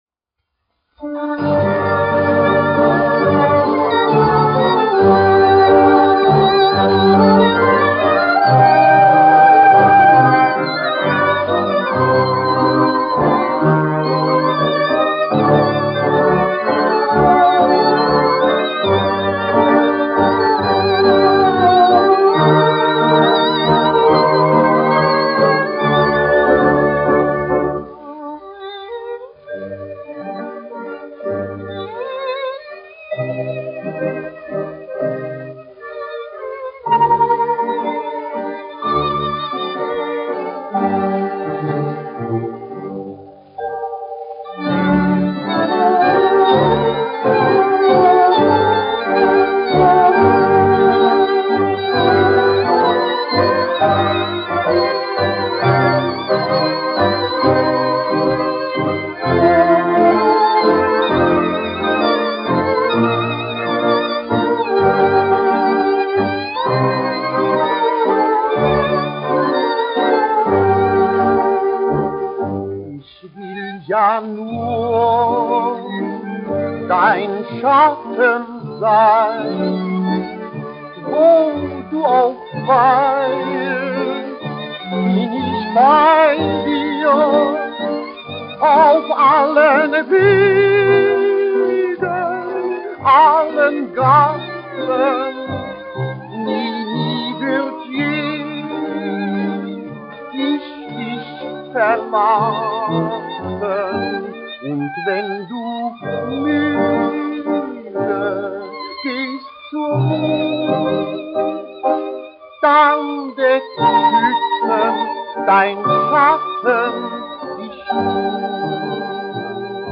1 skpl. : analogs, 78 apgr/min, mono ; 25 cm
Populārā mūzika